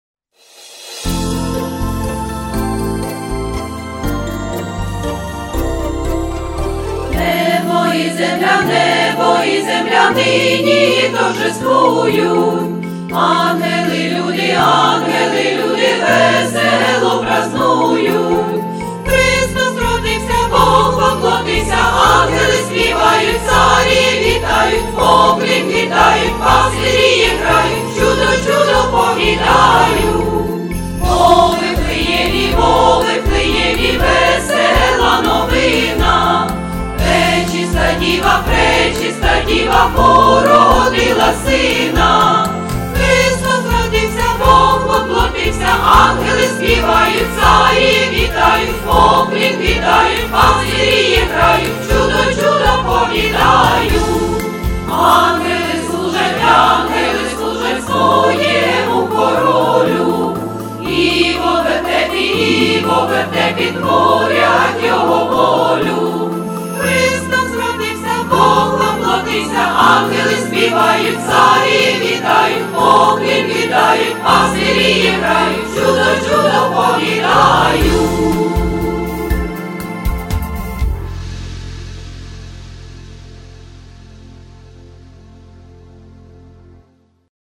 вокальний ансамбль